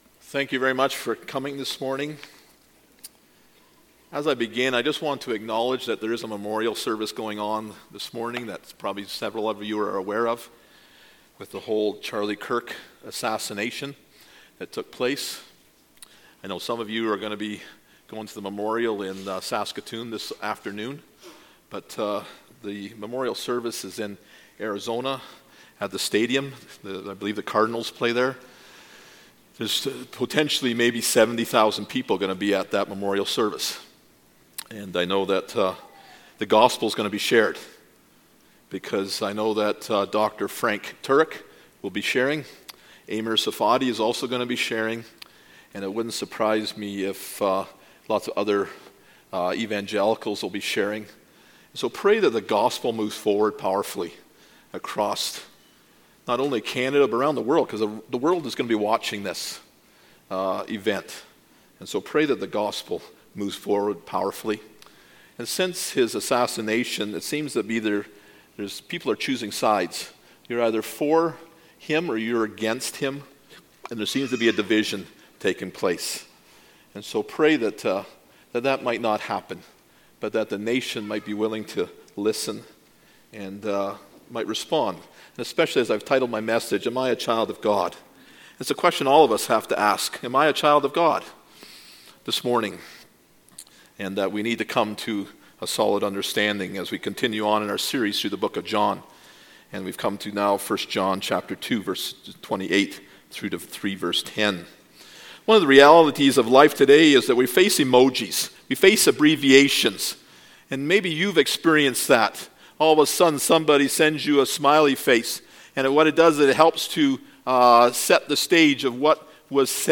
Who You Are in Jesus Passage: 1 John 2:28-3:10 Service Type: Sunday Morning « An Invitation to the Table Love Is a Cross